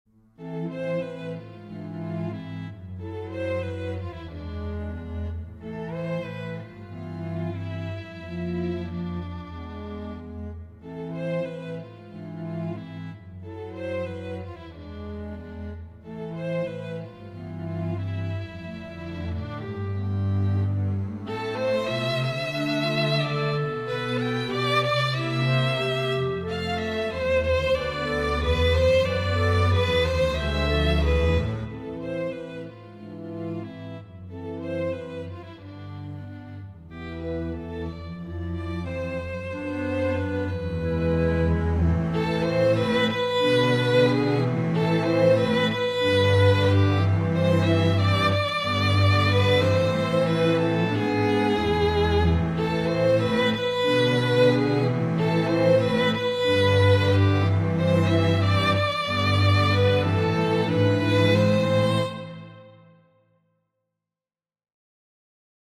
Quartet for Clarinet Quartet (Clarinet, Violin, Cello and Double Bass) (INCOMPLETE)
The polychords are starting to make the music sound much more adventurous, and perhaps more like Ravel.